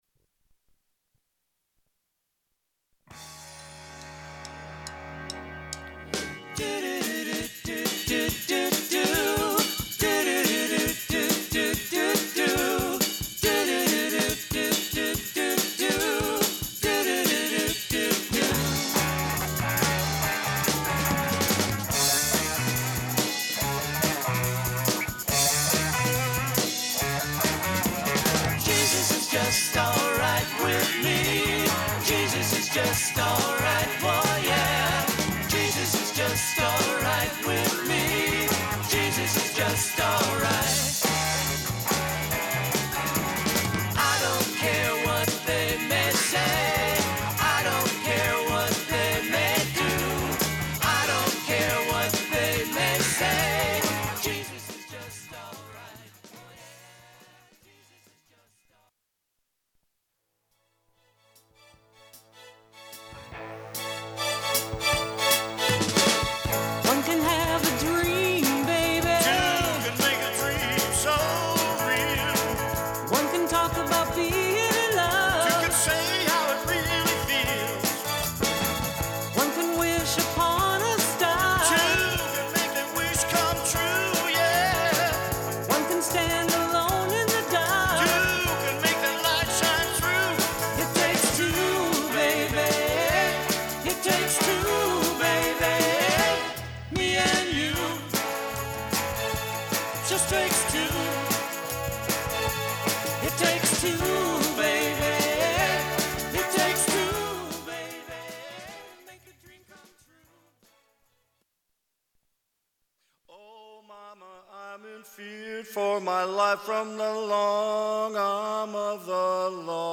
classic rock band